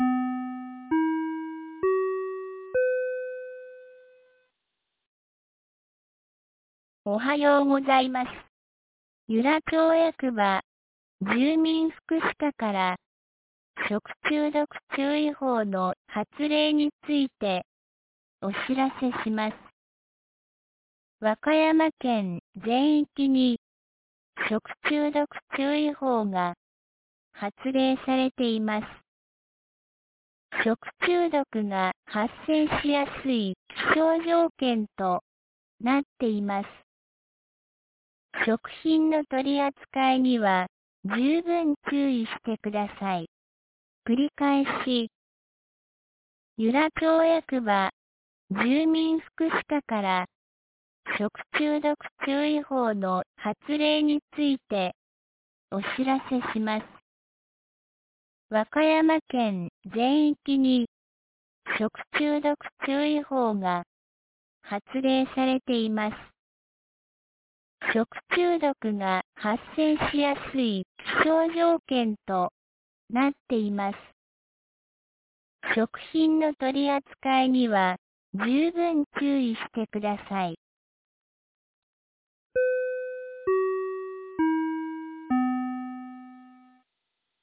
2019年08月11日 07時51分に、由良町より全地区へ放送がありました。